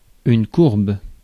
Ääntäminen
Synonyymit anse Ääntäminen France Tuntematon aksentti: IPA: /kuʀb/ IPA: /kuʁb/ Haettu sana löytyi näillä lähdekielillä: ranska Käännöksiä ei löytynyt valitulle kohdekielelle.